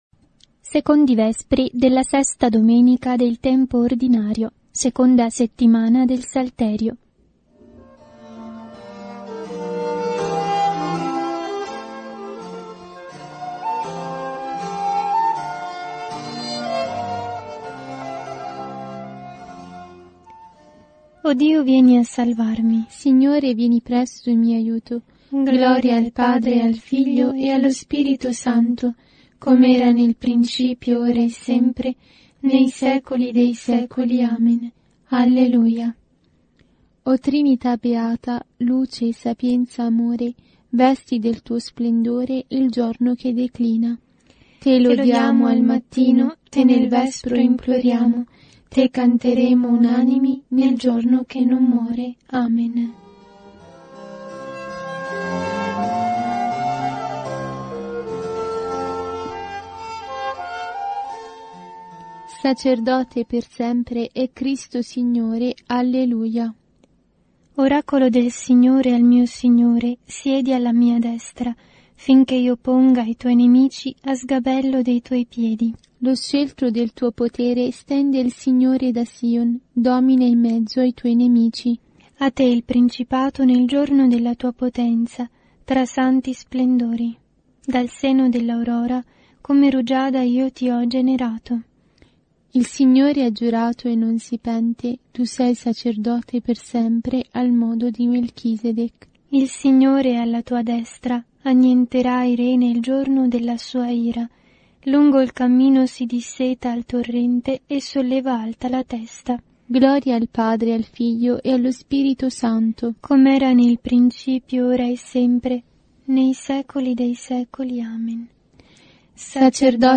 Secondi Vespri – VI° Domenica T.O. – Anno A